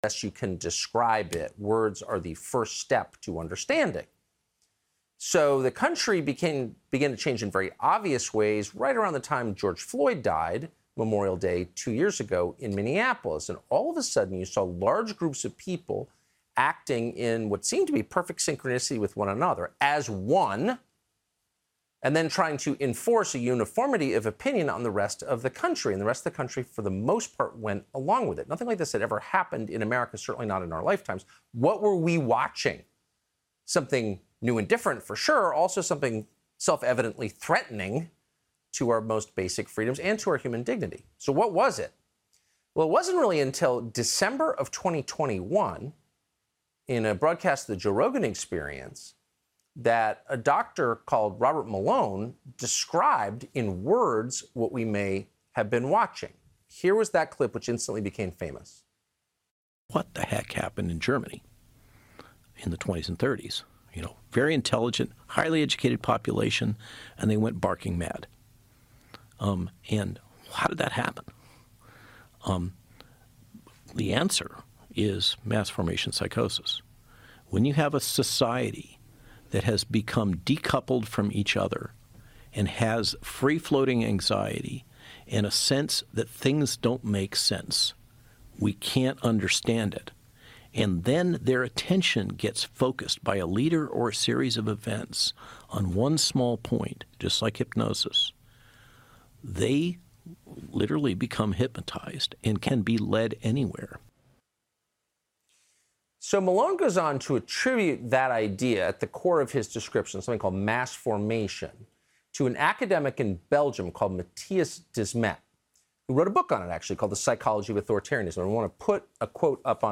Discussing Mass Formation The COVID pandemic changed the world forever: as people were forced into isolation, anxiety became the norm. But Professor Mattias Desmet says we've seen this phenomenon before. It's called 'mass formation', as he joins Tucker Carlson and then Del Bigtree to explain what it means.